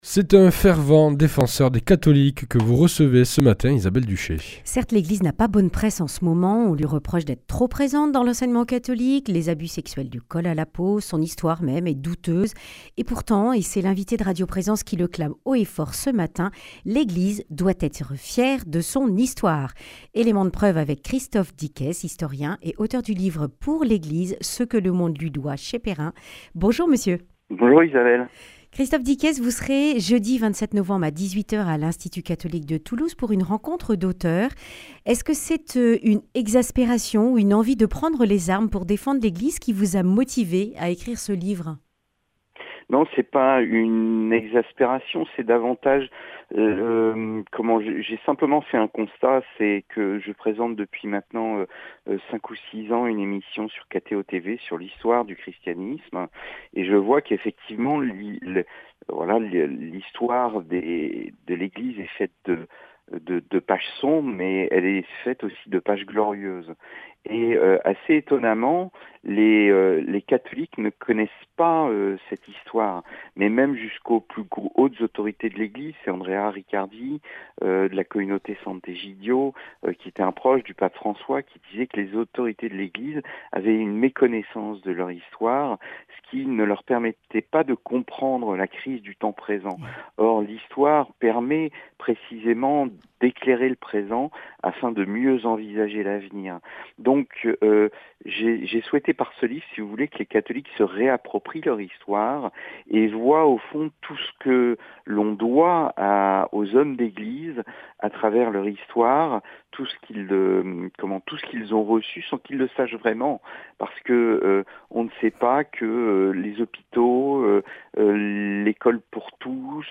Accueil \ Emissions \ Information \ Régionale \ Le grand entretien \ Le monde doit tant à l’Eglise !